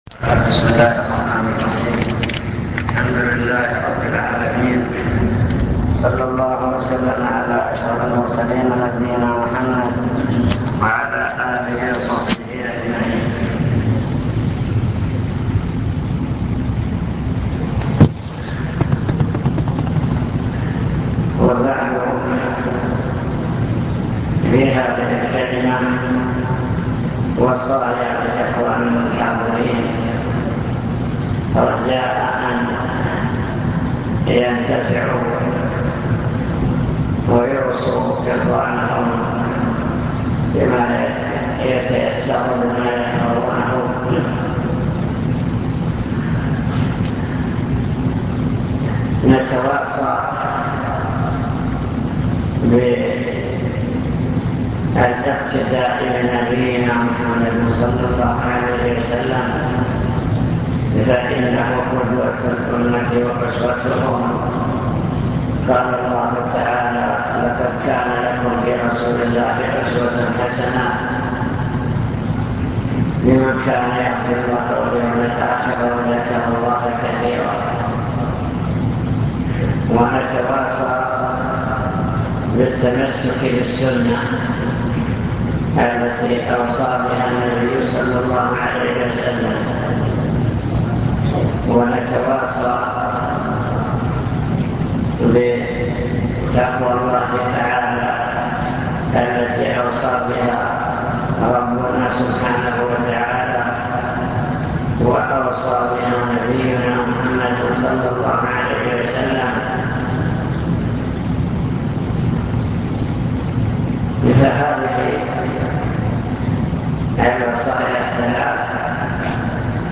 المكتبة الصوتية  تسجيلات - محاضرات ودروس  محاضرة في الزلفى مع شرح لأبواب من كتاب الجنائز في صحيح البخاري